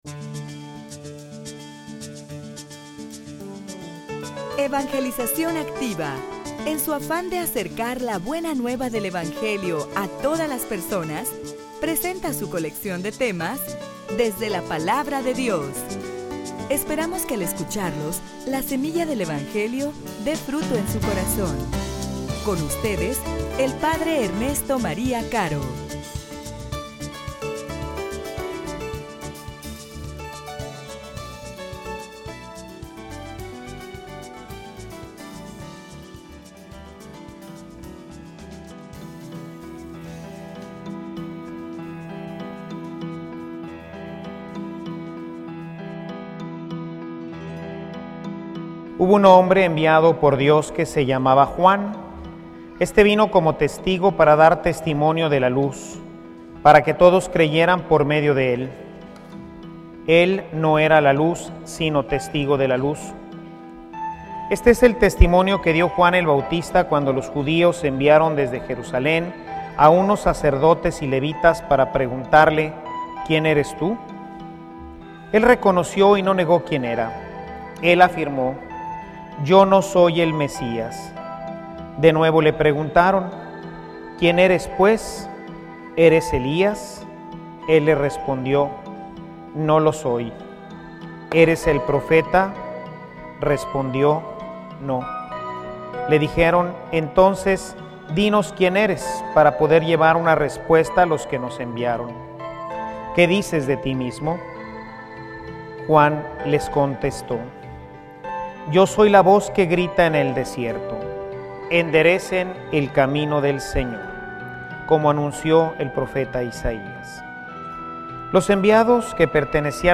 homilia_Que_no_se_contamine_tu_vida.mp3